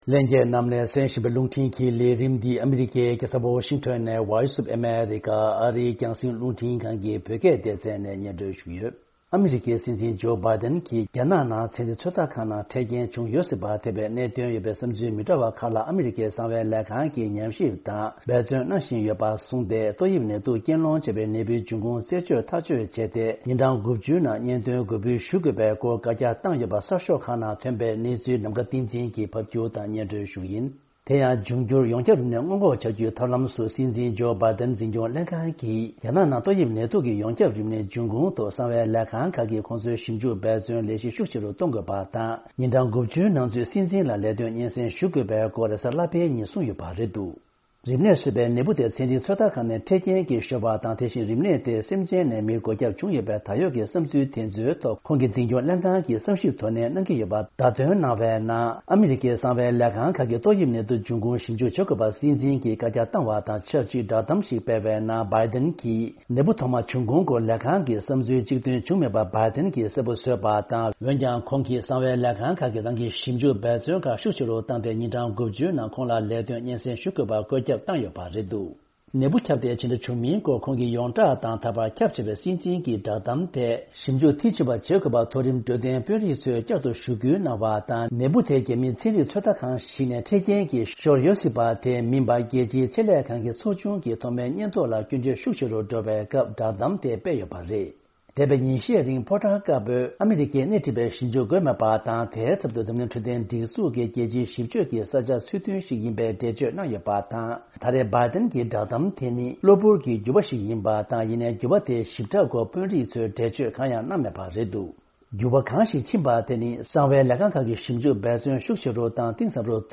ཕབ་ སྒྱུར་དང་སྙན་སྒྲོན་ཞུ་ཡི་རེད།།